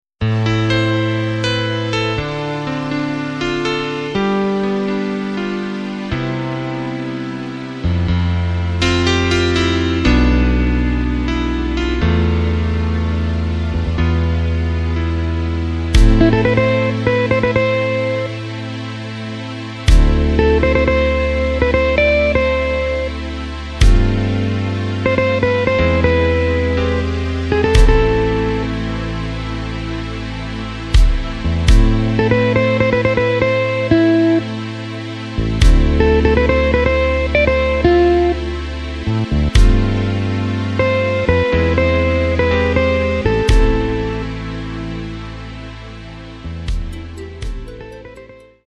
Takt:          4/4
Tempo:         122.00
Tonart:            C